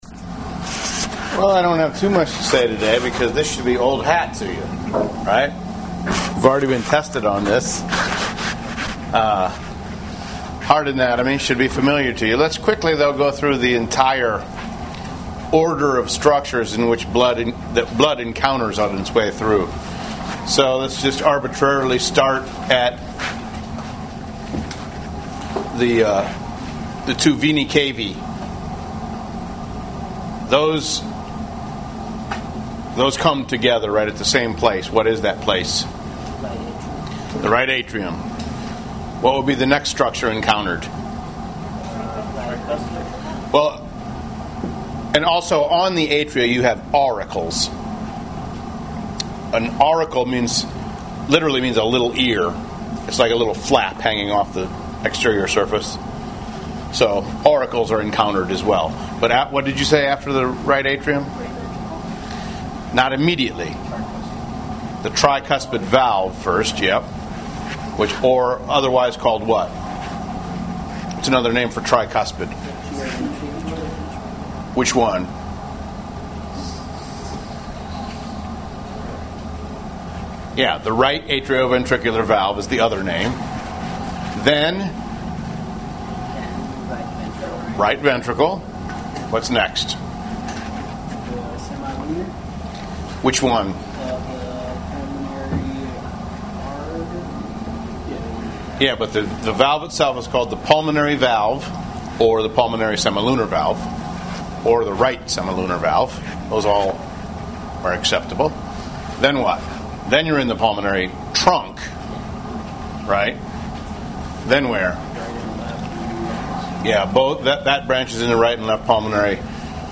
Laboratory Recordings
After the course begins, the link to a lecture recording will appear within hours after the lecture is given in class.